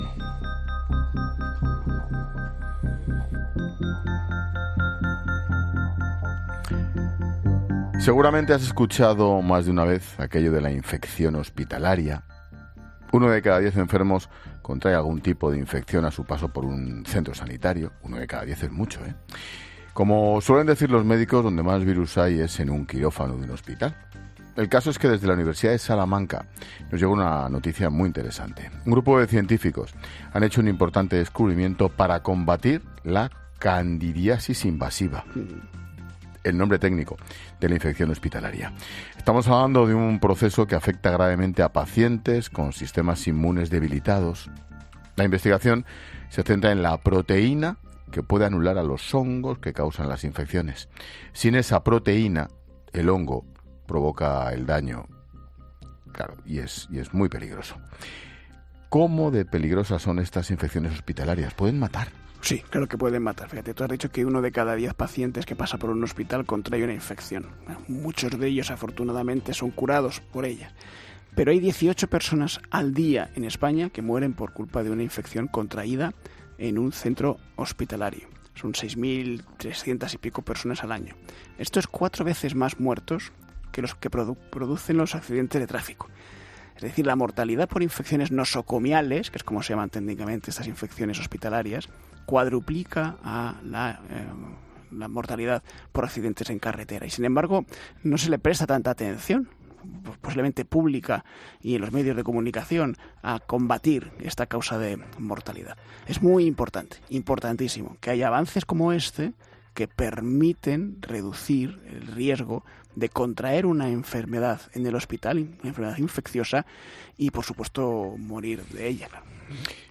Jorge Alcalde explica a Ángel Expósito la peligrosidad de las infecciones hospitalarias en la sección sobre ciencia de cada miércoles